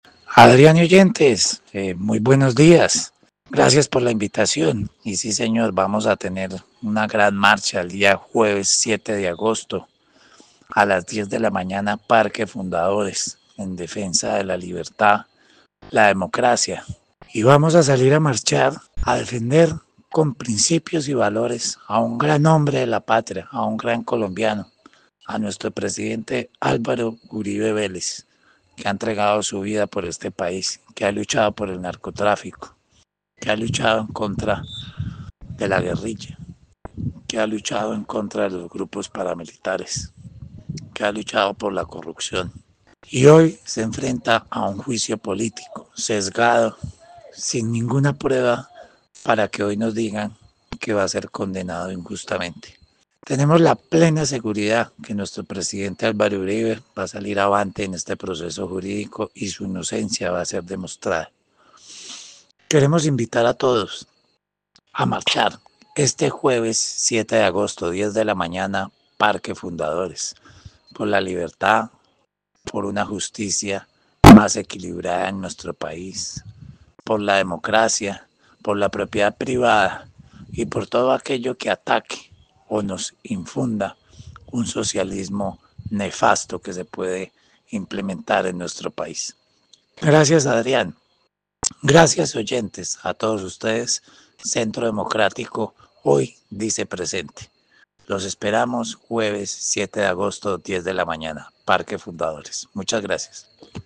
uno de los líderes del Centro Democrático en el Quindío en diálogo con Caracol Radio Armenia explicó “vamos a tener una gran marcha el día jueves 7 de agosto a las 10 de la mañana desde el Parque de Los Fundadores en defensa de la libertad